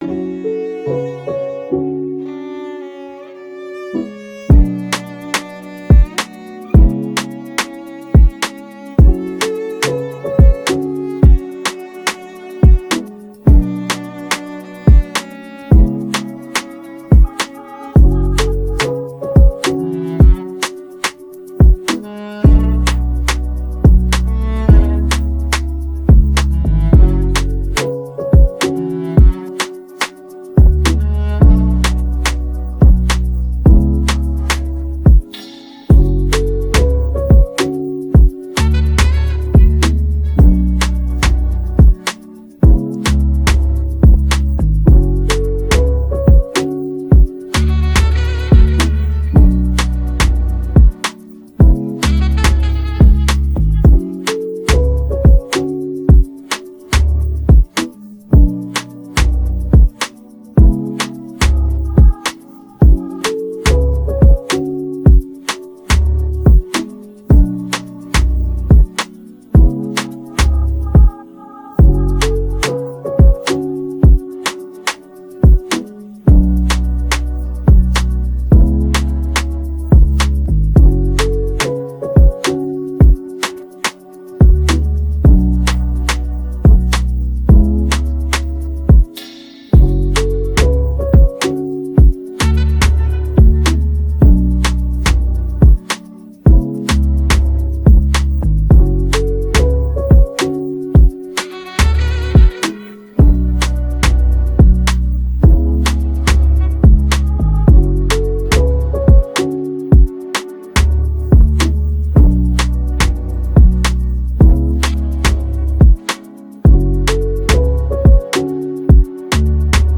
Afro popAfrobeatshiphop trap beats